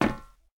immersive-sounds / sound / footsteps / rails / rails-01.ogg
rails-01.ogg